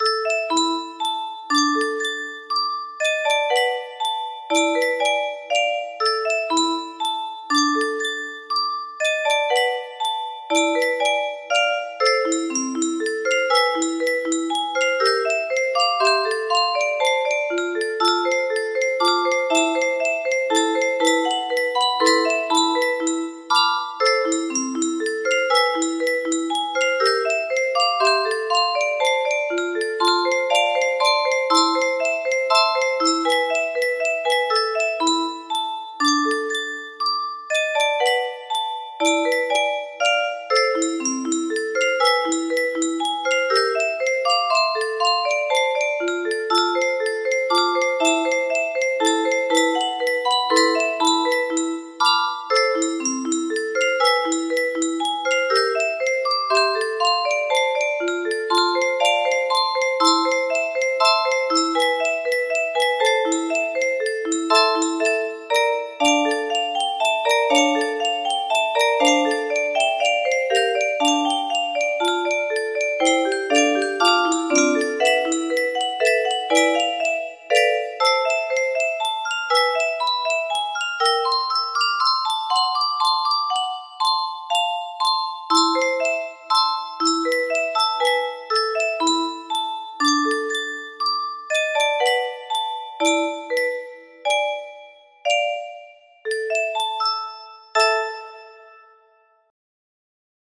Grand Illusions 30 (F scale)
for Grand Illusion 30 (F scale) Music Boxes